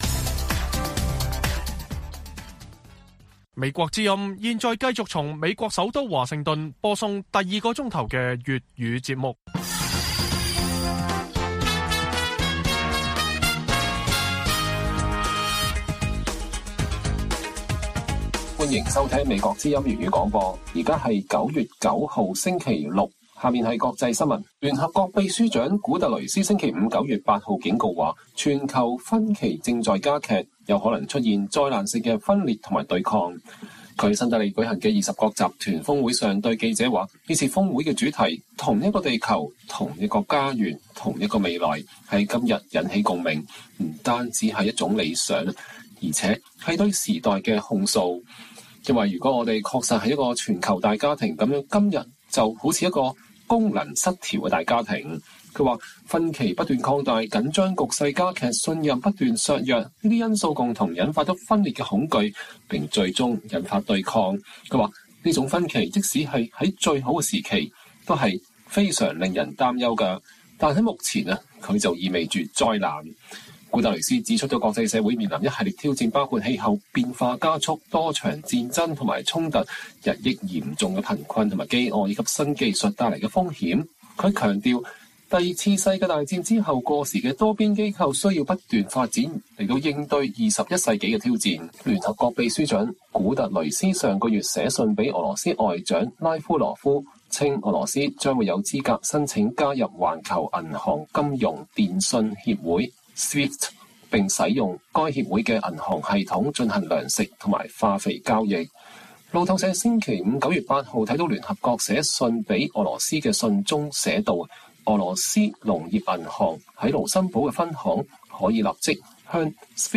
粵語新聞 晚上10-11點 : 分析人士說 習近平缺席G20峰會既錯失機遇也錯失策略